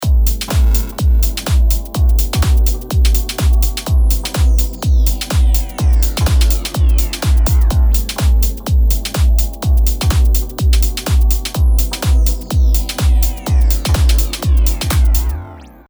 なので、4小節に1回クライマックスが来るように設定してみましょう。